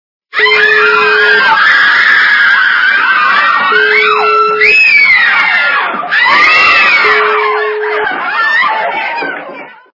» Звуки » Смешные » Визги - в женской раздевалке
При прослушивании Визги - в женской раздевалке качество понижено и присутствуют гудки.
Звук Визги - в женской раздевалке